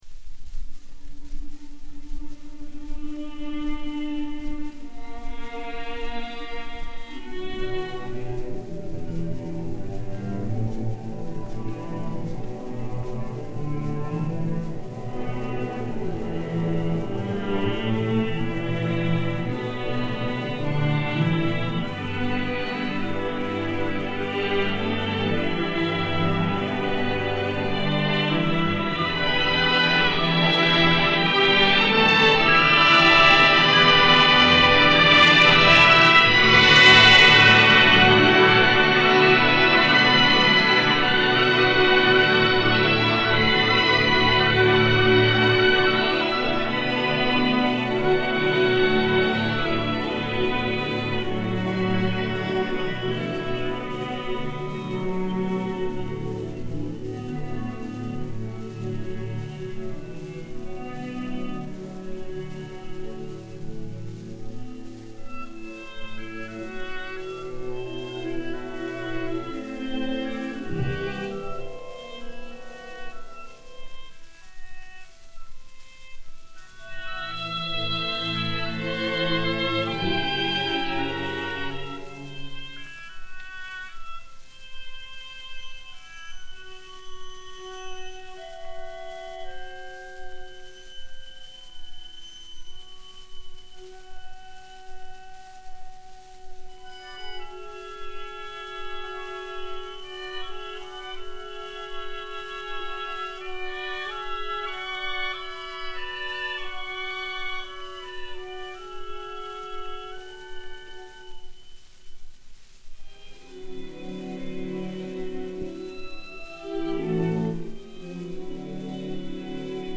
a) Mazurka - b) Valse
Orchestre Symphonique dir. Philippe Gaubert